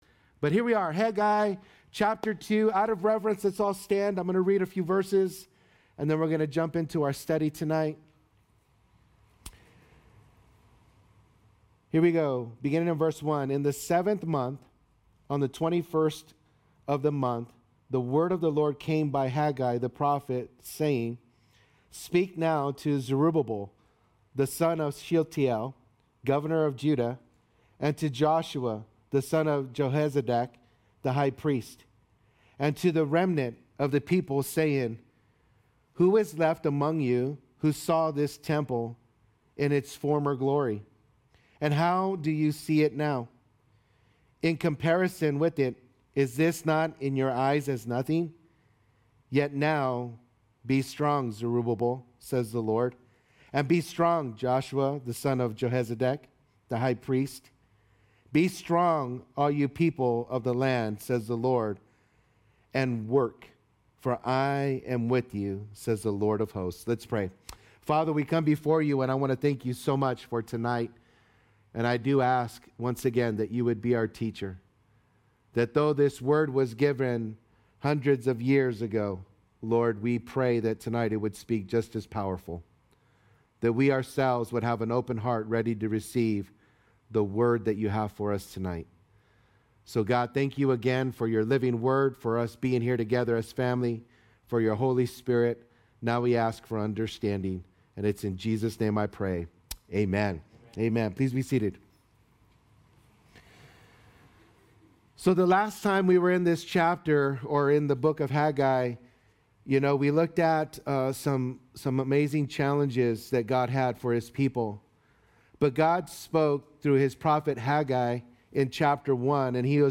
Calvary Chapel Saint George - Sermon Archive